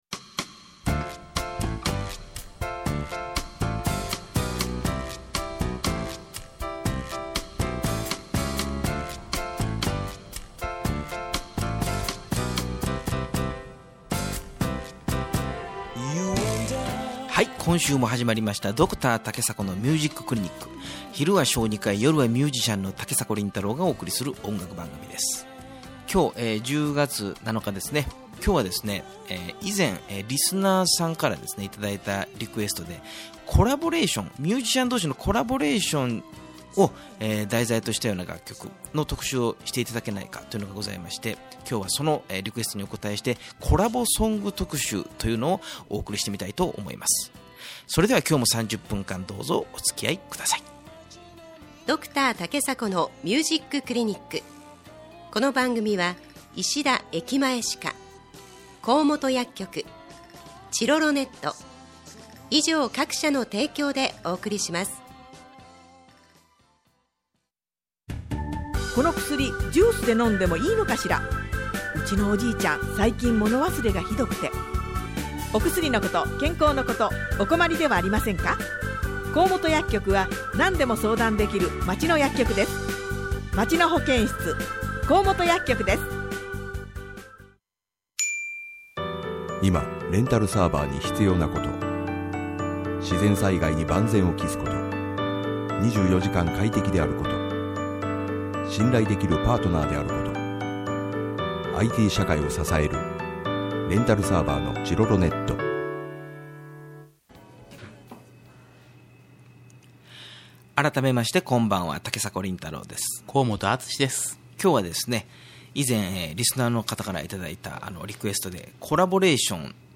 第44回放送録音をアップしました。